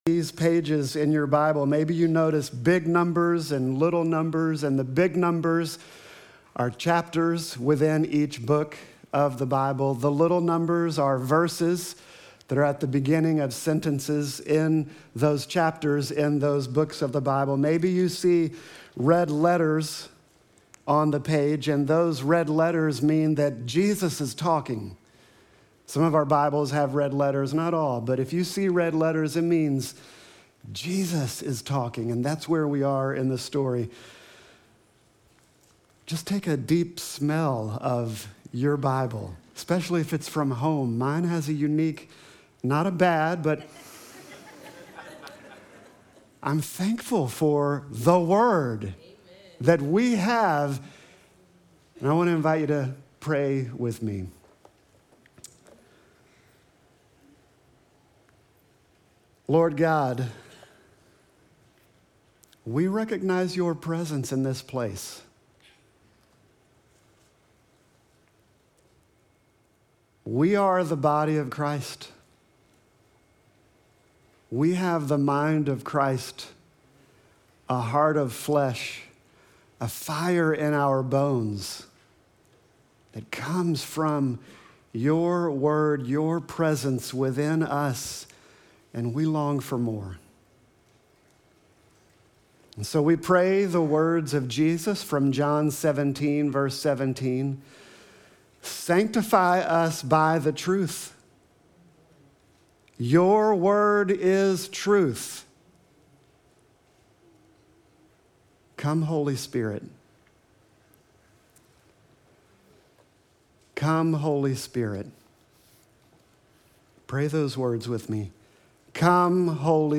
Sermon text: John 1:1-5